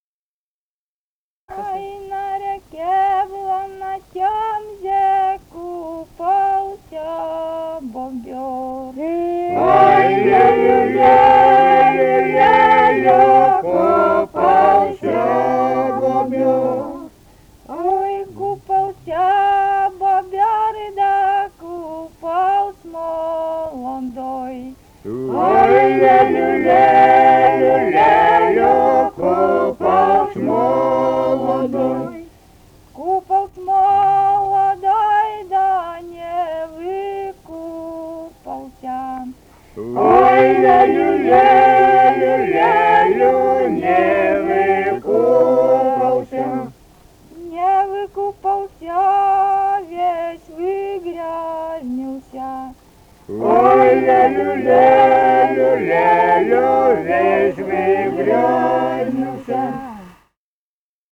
Костромская область, пос. Кадый Кадыйского района, 1964 г. И0794-04